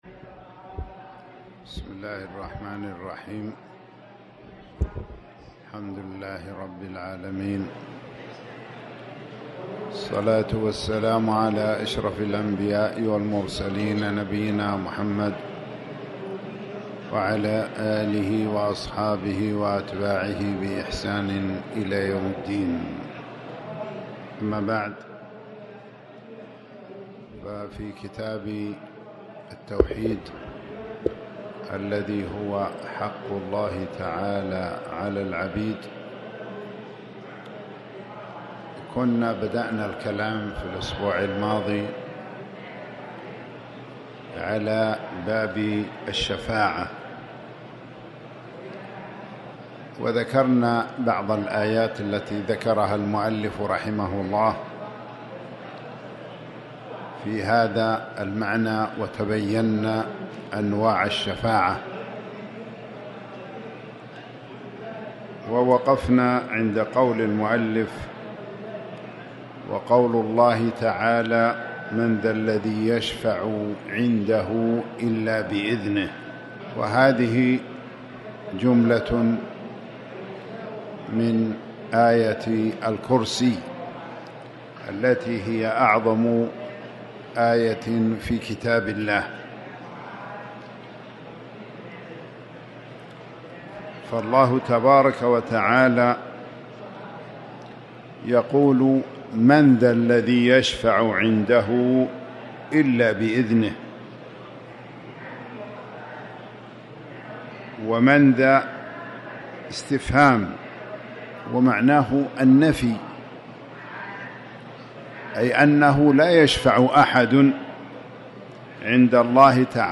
تاريخ النشر ٢٤ شوال ١٤٣٩ هـ المكان: المسجد الحرام الشيخ